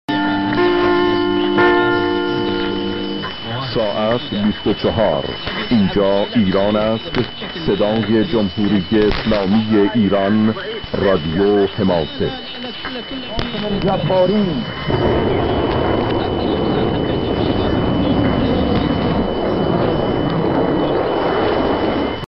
Now very strong on 585 kHz, mp3 with the ID attached, recorded at 2030 UTC (0000 Tehran time). They using the “normal” IRIB interval signal.